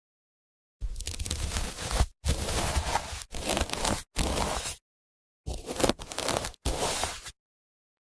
Pizza ASMR🔥